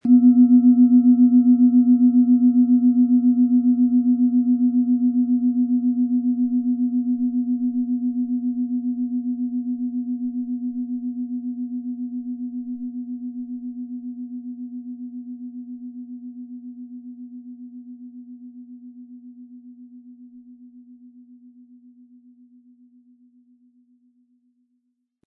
Sie entfaltet einen klaren Klang, der das Herz öffnet und das Gemüt aufhellt.
Höchster Ton: Hopi-Herzton
• Sanfter, heller Klang: Weckt Freude, stärkt Selbstvertrauen
PlanetentonSonne & Hopi-Herzton (Höchster Ton)
MaterialBronze